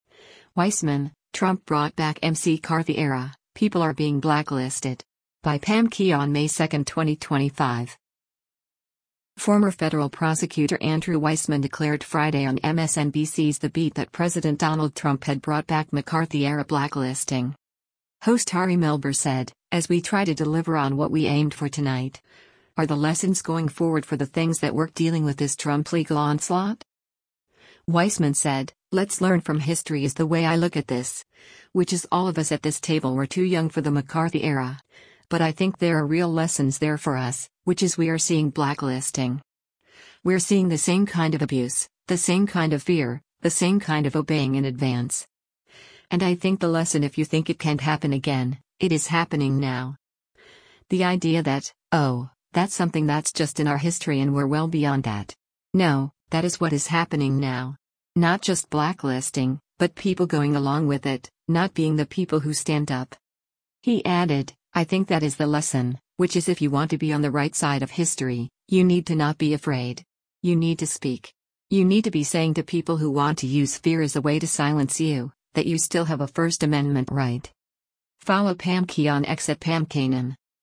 Former federal prosecutor Andrew Weissmann declared Friday on MSNBC’s “The Beat” that President Donald Trump had brought back “McCarthy era” blacklisting.